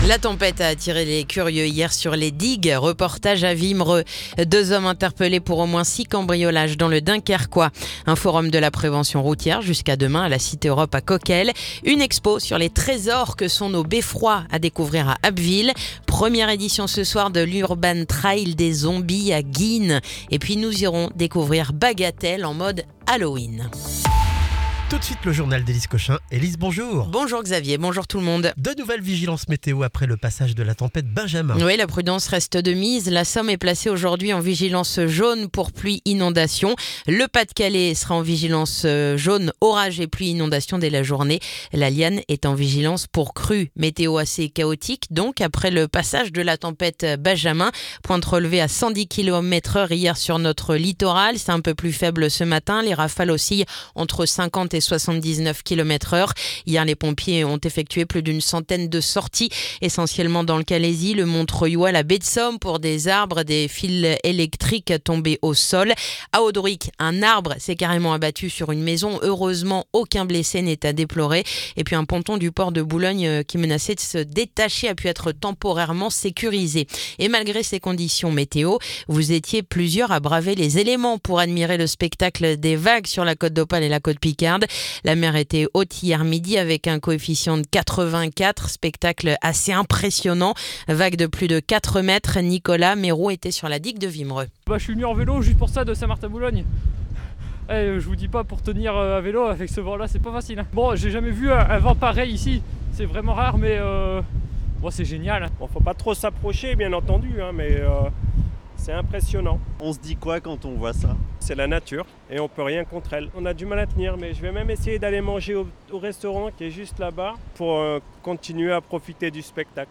Le journal du vendredi 24 octobre